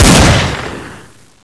deagle-1.wav